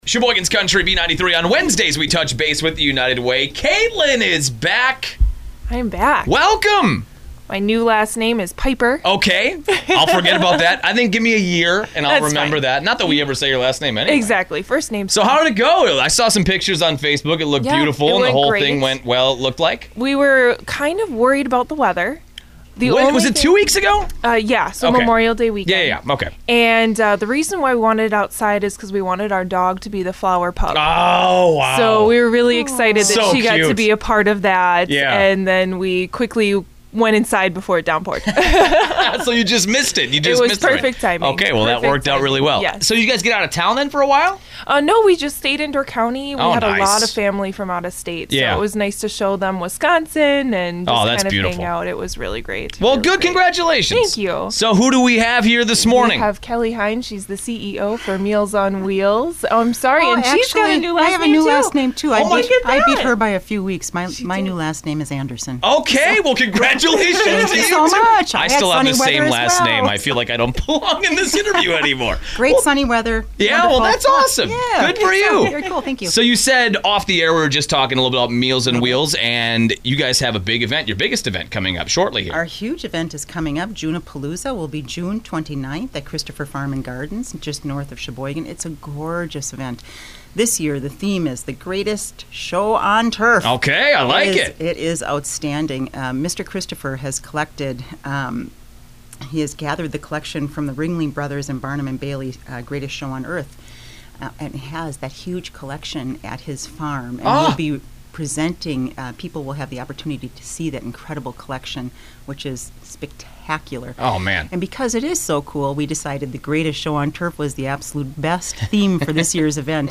Listen every week on Wednesday mornings to learn about local nonprofit news.